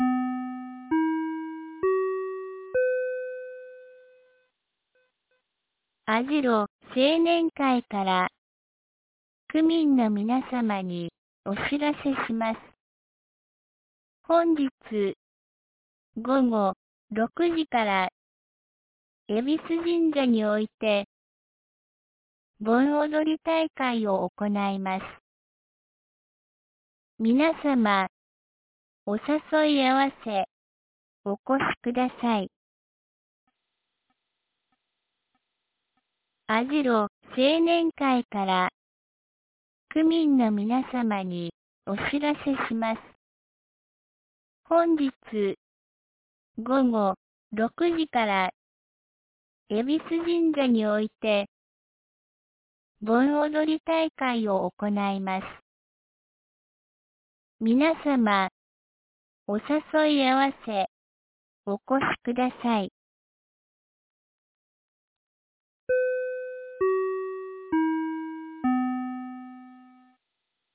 2025年08月13日 12時21分に、由良町から網代地区へ放送がありました。